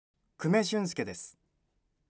ボイスサンプルはこちら↓